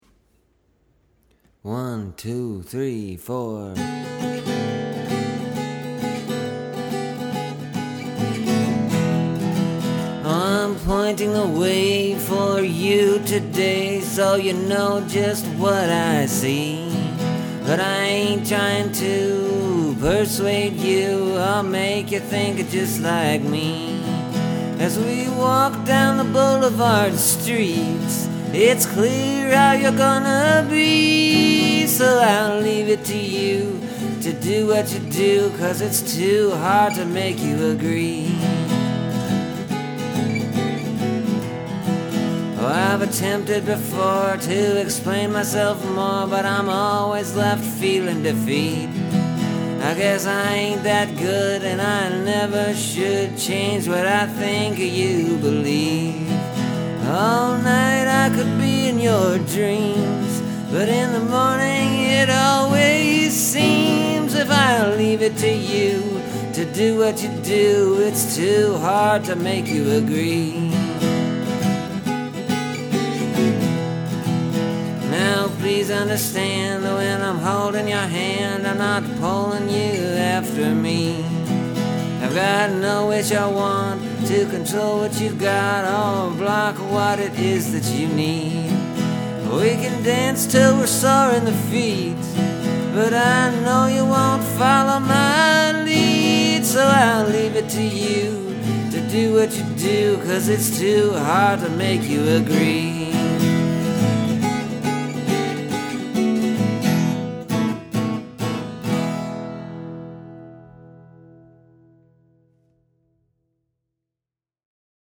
Here’s a song I wrote Sunday night. I was just kinda working around on the guitar and a part came out and then some words just kinda happened.